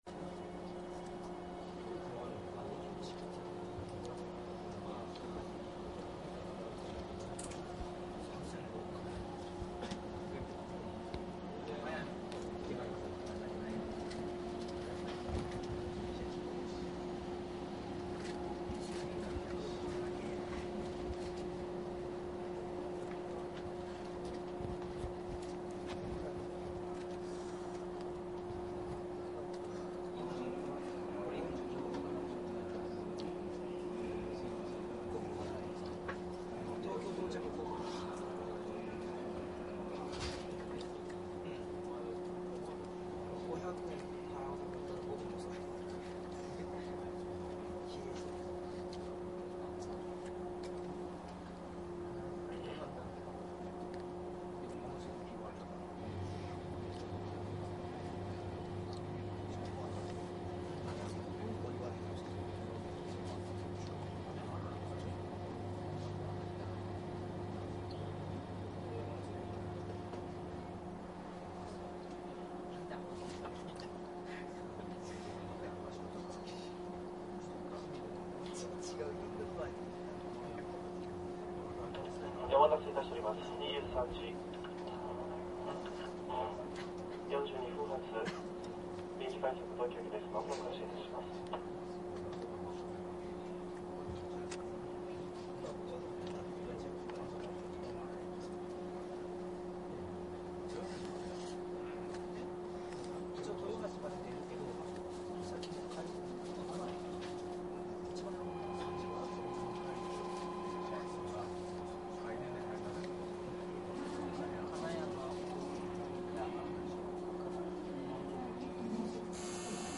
商品説明JR167系 臨時快速【前編】 走行音 ＣＤ ♪
東海道線の上り臨時快速運転を名古屋～静岡間で録音。豊橋あたりまではところどころ会話が聞こえます。
■【臨時】名古屋→静岡 モハ167－9＜DATE99－3－28＞
マスター音源はデジタル44.1kHz16ビット（マイクＥＣＭ959）で、これを編集ソフトでＣＤに焼いたものです。